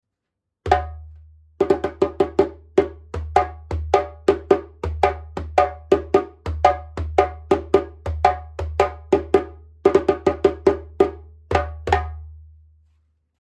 ボディ マリ 打面 35cm、高さ59cm、重量7.5kg 木材 メリナ ヤギ皮 マリ（やや厚め） 縦ロープ：
メリナウッドの真骨頂、35センチの大口径でこのサウンドと肉厚ボディーで7.5キロ